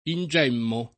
ingemmo [ in J$ mmo ]